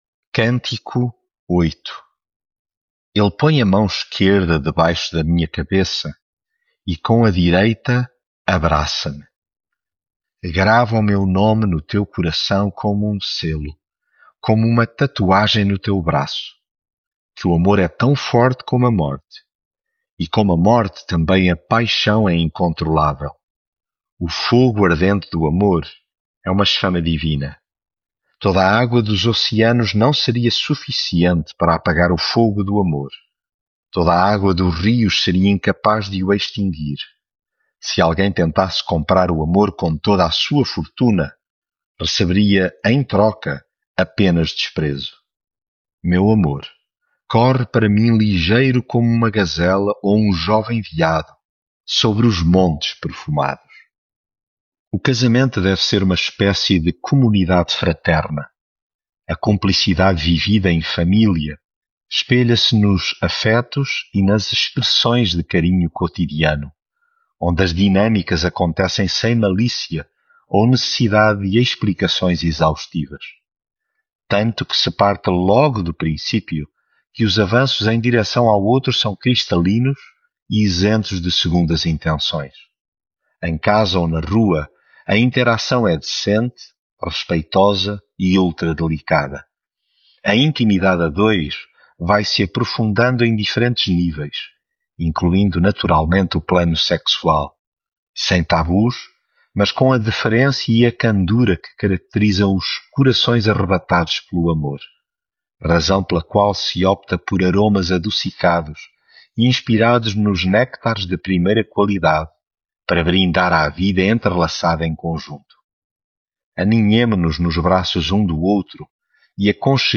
Devocional
Leitura em Cantares 8